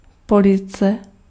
Ääntäminen
IPA: [ʁɛ.jɔ̃]